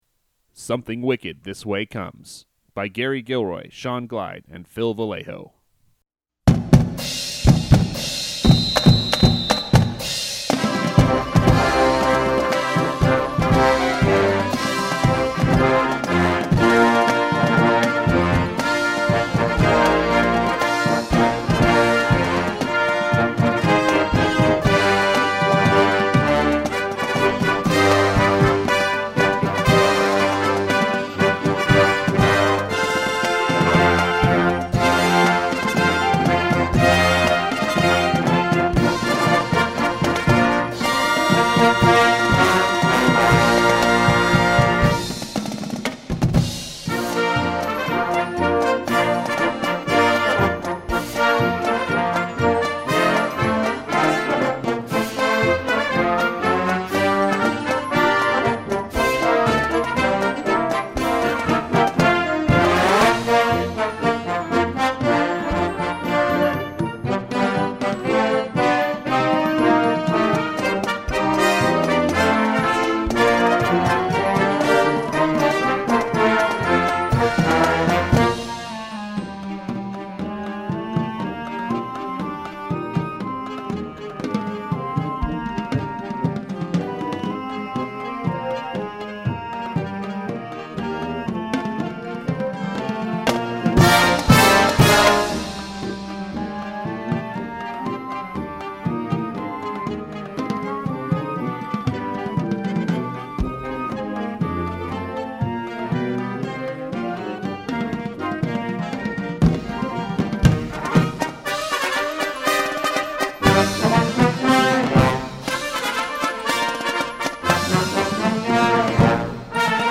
circus themed Marching Band Show